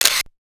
Camera Shutter 1.wav